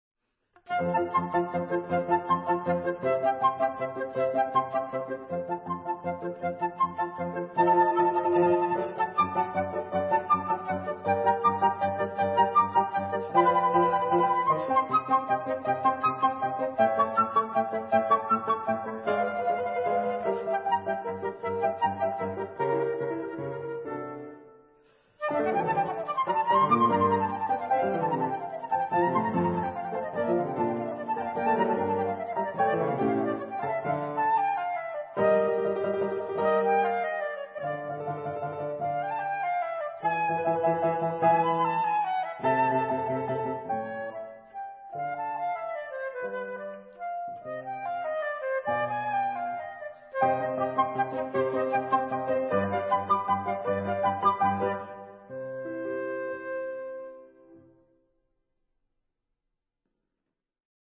flûte
piano
Dans une atmosphère douce et apaisante, le duo flûte et piano évoque tour à tour le romantisme, le mystère et l’univers du rêve, à travers des œuvres venues d’Italie, de France, du Canada et d’Asie.
V. Presto